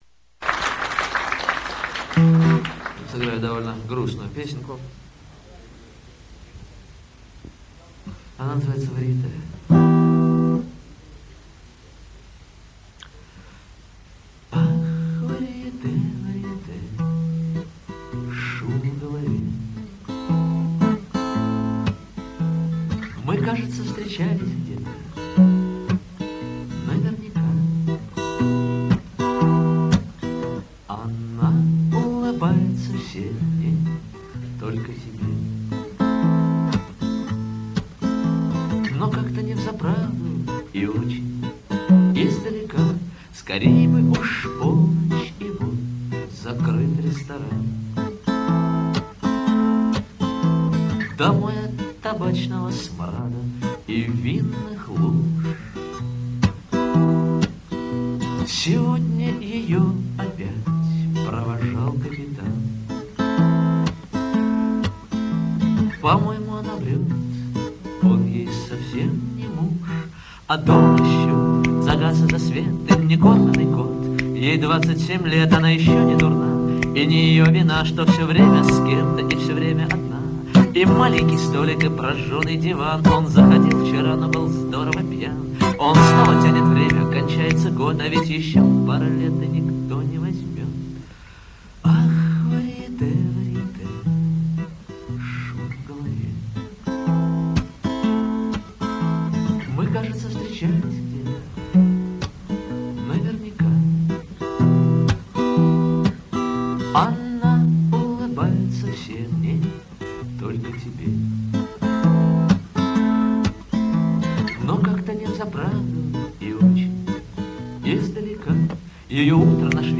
Сольный концерт
в московской школе